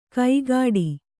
♪ kaigāḍi